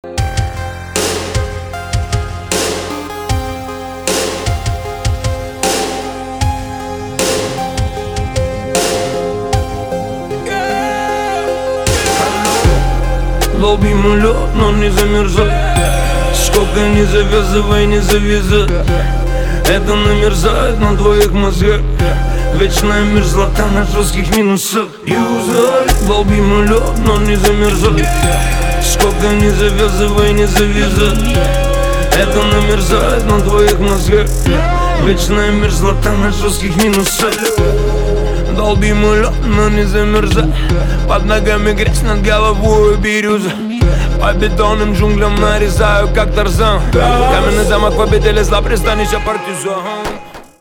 • Качество: 320, Stereo
русский рэп
мощные басы
качающие